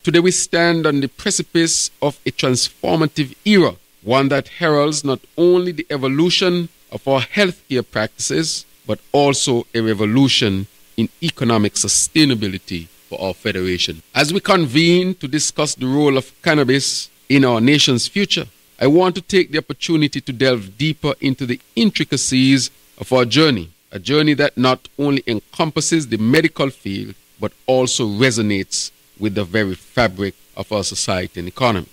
The first ever “CannaBliss” Festival was held in St. Kitts on Friday, December 29th to Saturday December 30th, at the Marriott’s Resort.
Speaking on the timeliness of the event was Prime Minister and Minister of Health, Hon. Dr. Terrance Drew: